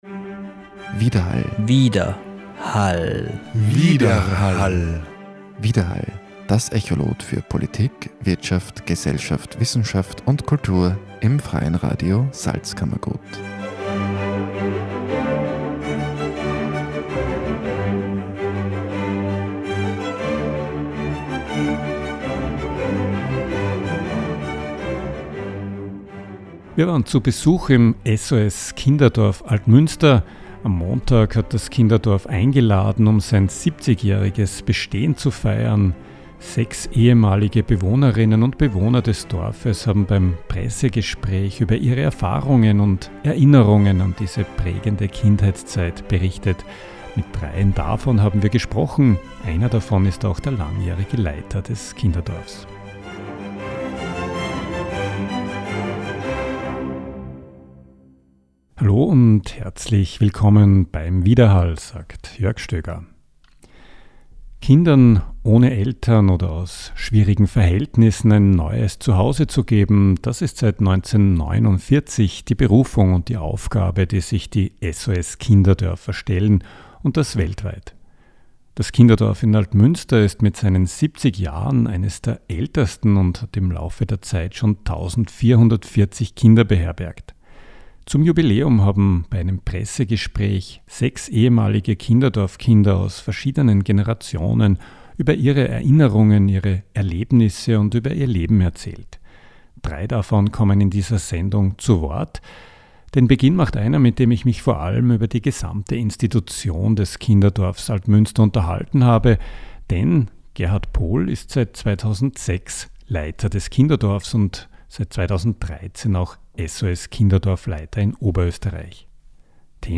Das Kinderdorf in Altmünster ist mit seinen 70 Jahren eines der ältesten und beherbergte im Laufe der Zeit schon 1440 Kinder. Zum Jubiläum erzählten bei einem Pressegespräch sechs ehemalige Kinderdorf Kinder aus verschiedenen Generationen über ihre Erinnerungen, ihre Erlebnisse und über ihr Leben danach.